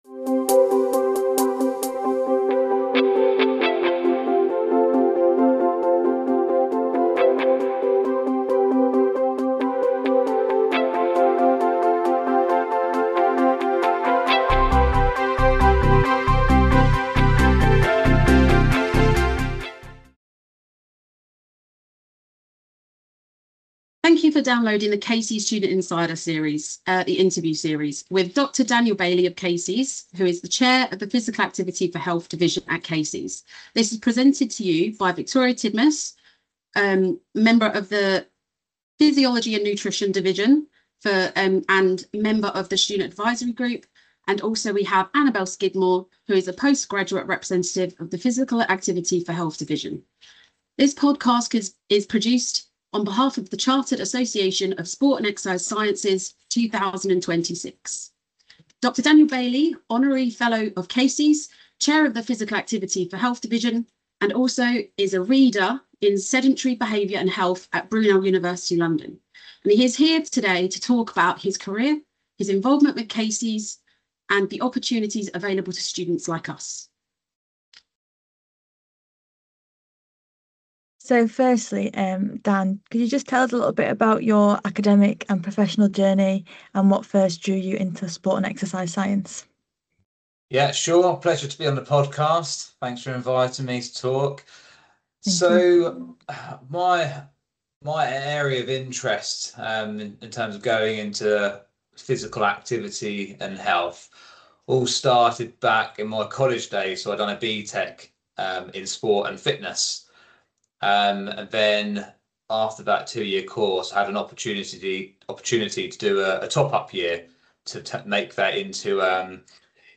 Student Insider Podcast: Episode 2